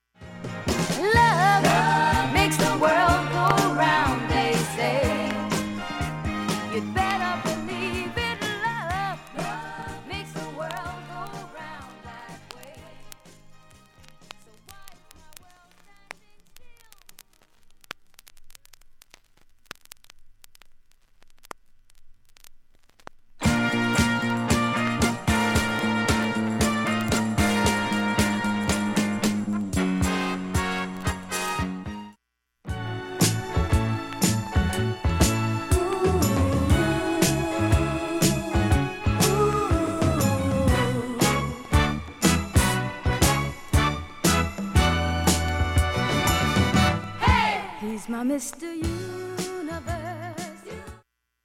盤面きれいです音質良好全曲試聴済み。
2,(33s〜)B-1始め６回プツ出ます。
ほか３回までのかすかなプツが３箇所
単発のかすかなプツが４箇所
グルーヴィー・ソウル